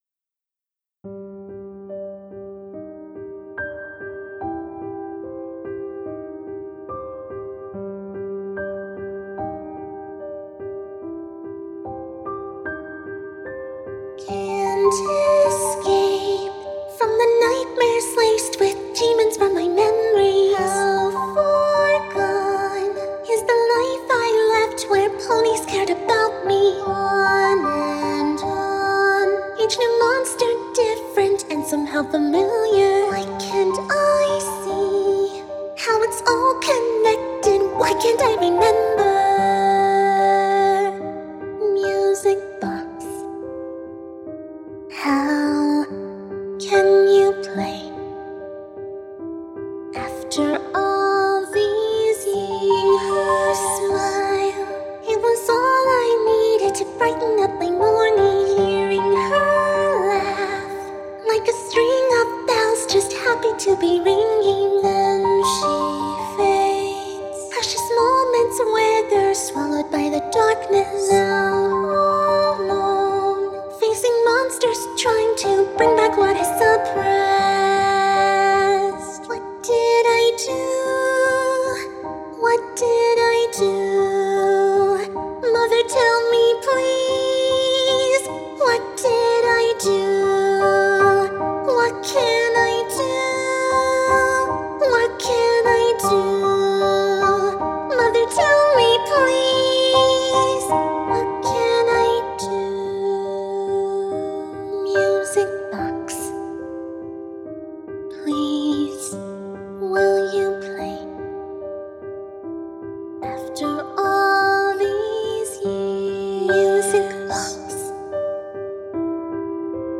A Melancholy Music Box...